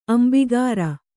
♪ ambigāra